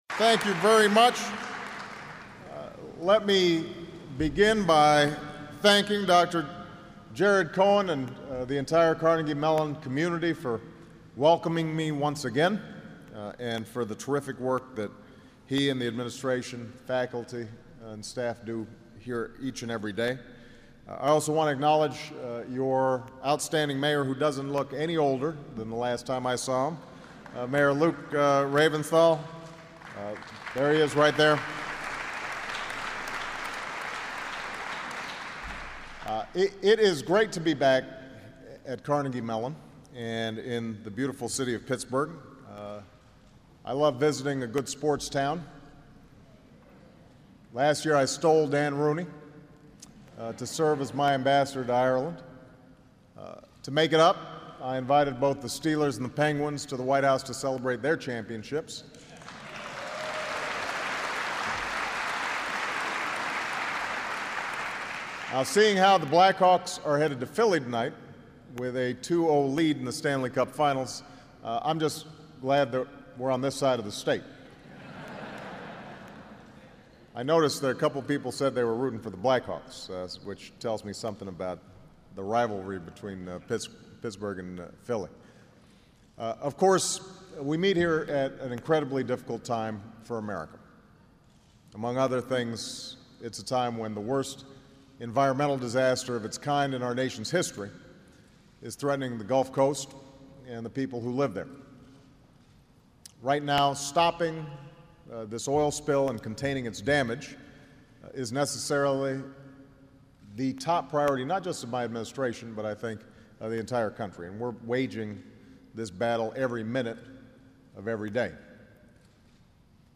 U.S. President Barack Obama speaks at Carnegie Mellon University
Recorded at Carnegie Mellon University, Pittsburgh, PA, June 2, 2010.